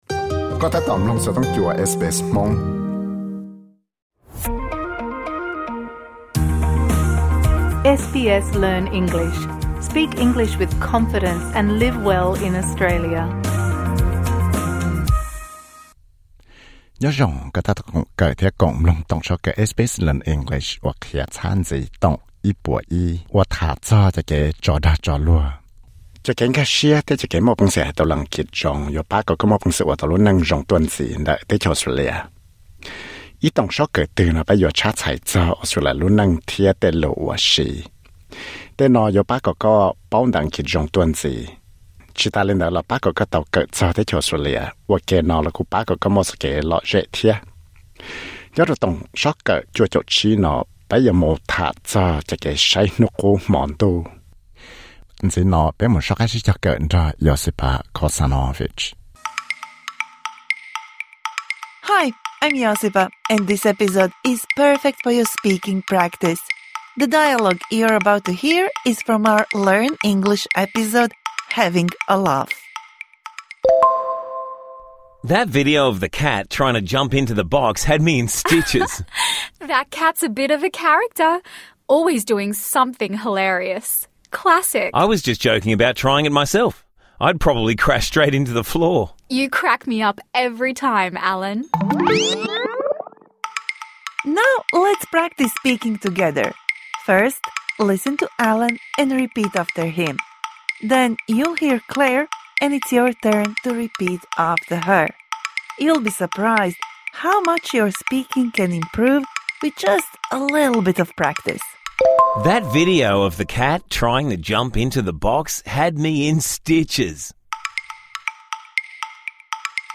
Toom sob kawm qhia txhab ntxiv no yuav pab kom kom tau xyaum tham siv cov lo lus thiab tej sob lus uas koj tau kawm ntawm toom sob kawm #101 Having a laugh (Tham txog cov kev tso dag tso luag -Med) Tsis txhob txaj muag - kav tsij xyaum tham!